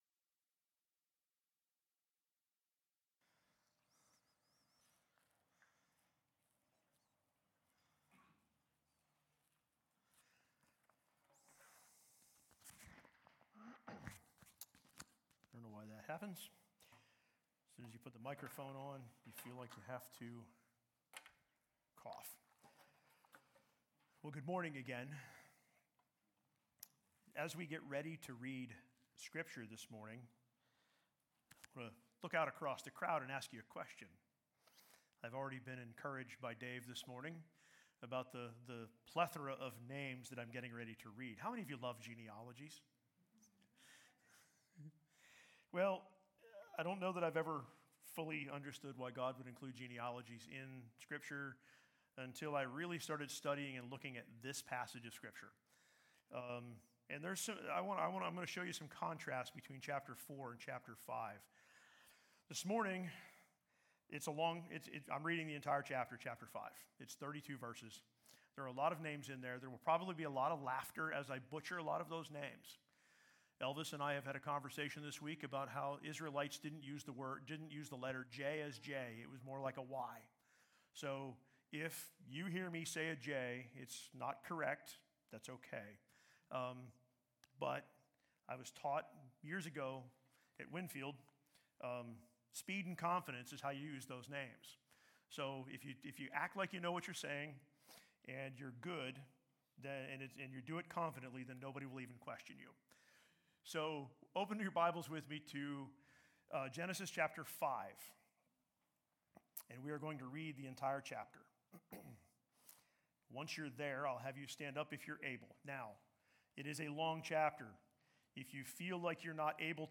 SERMONS | Sunbury City Church